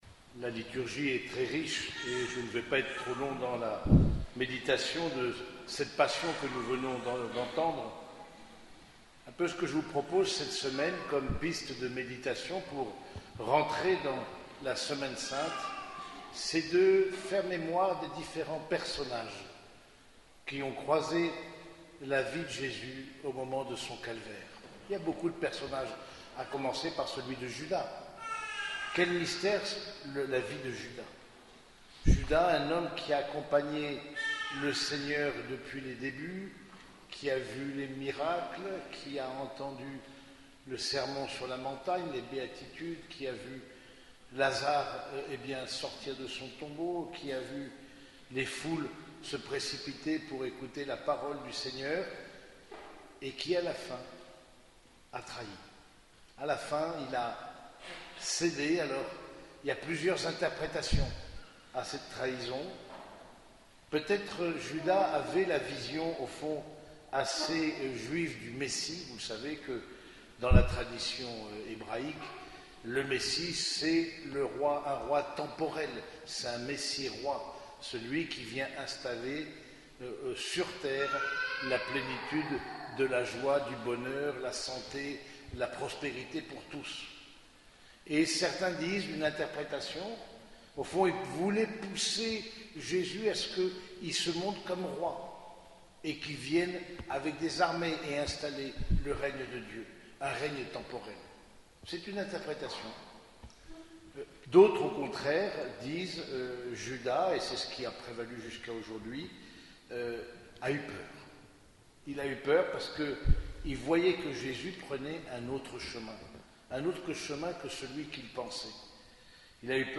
Homélie du dimanche des Rameaux et de la Passion